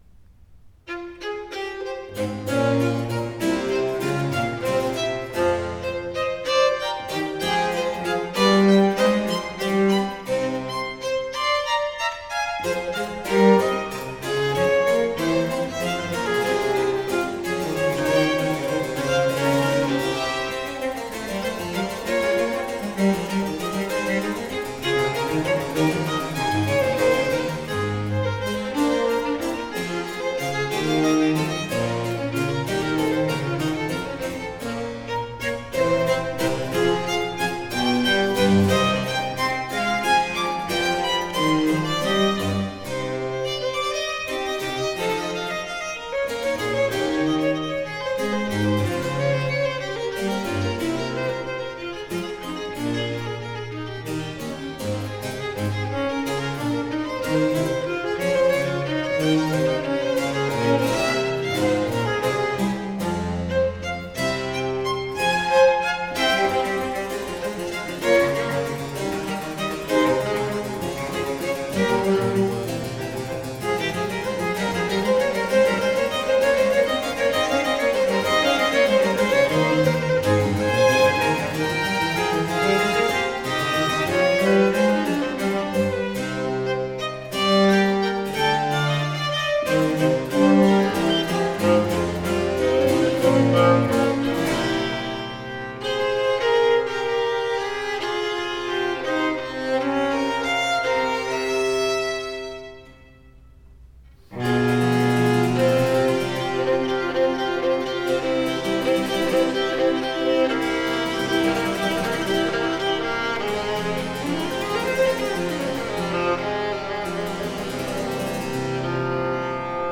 Vivace